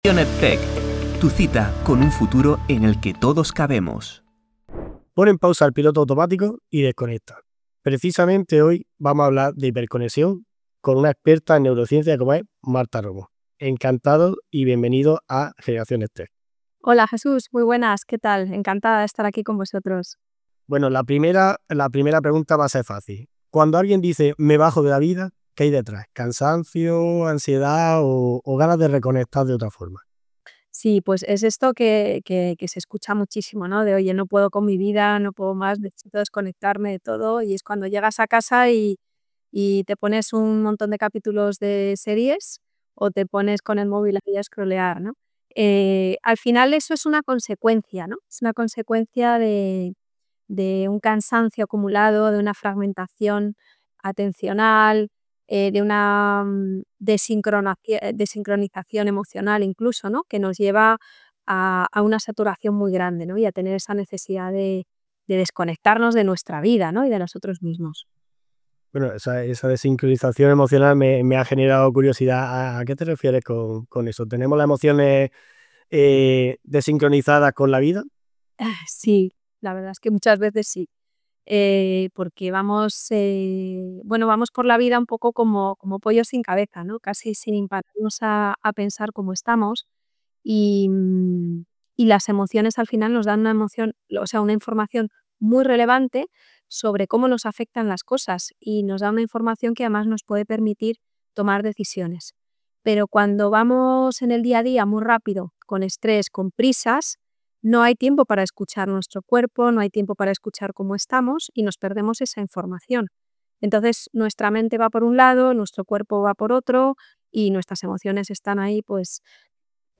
Entrevista a tu cerebro: cómo volver a estar presente